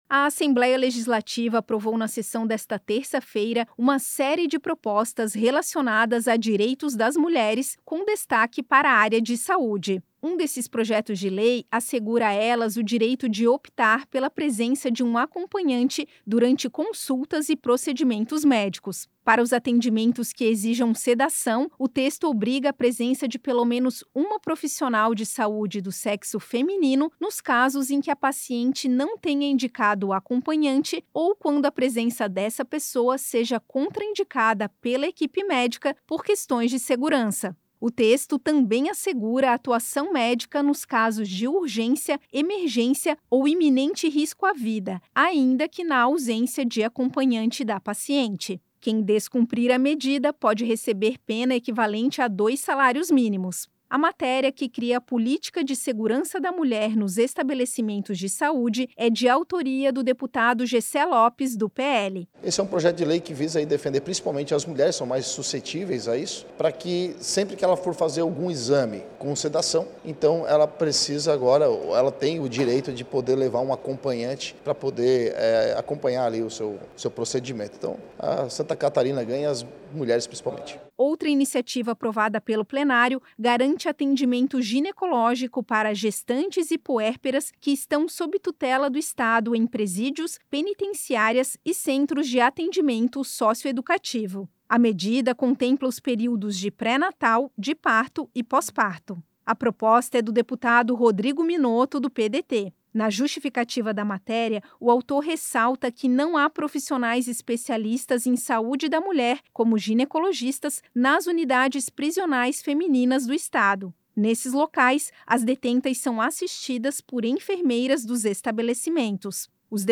Entrevista com:
-  deputado Jessé Lopes (PL), autor do PL 6/2023.